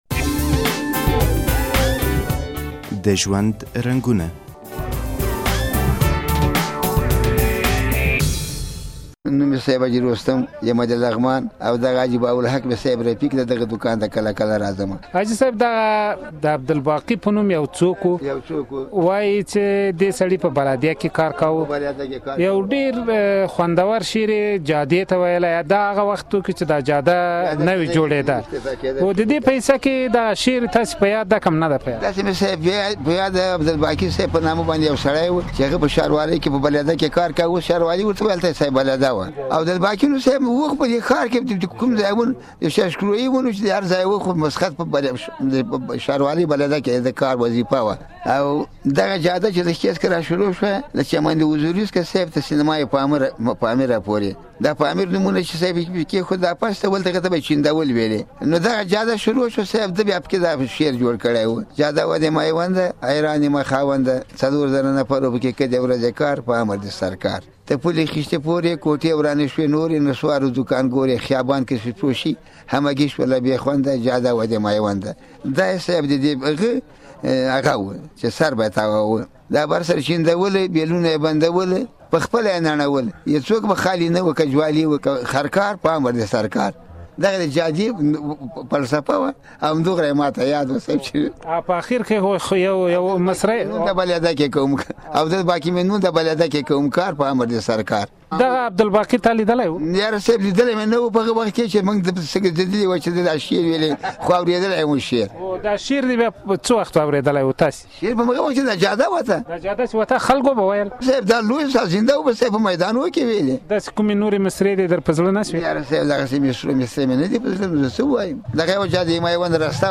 د کابل د جادې تاریخچه د شعر په ژبه له یوه سپین ږیري افغان څخه واورئ چې پخوا په کومو نومونو یادیده او اوس په کې څه بدلون ویني د پخوا په څیر.